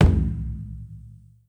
perc_17.wav